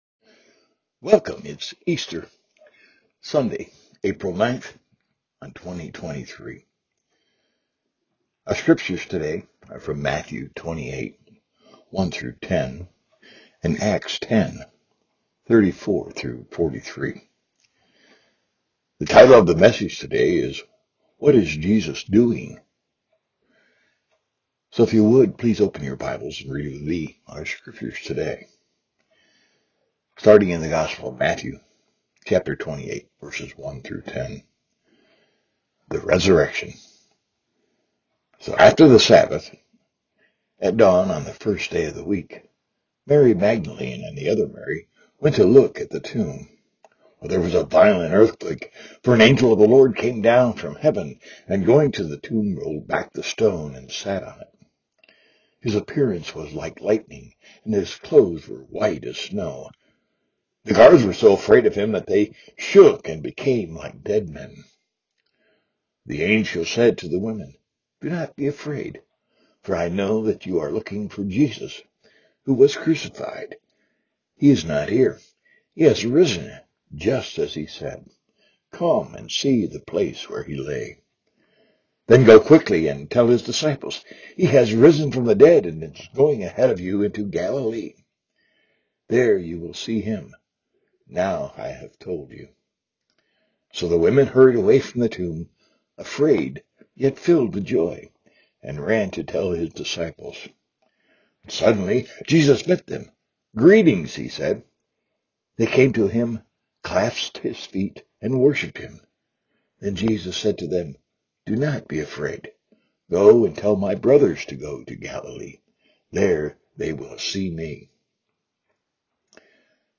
Easter Worship Service – April 9, 2023 « Franklin Hill Presbyterian Church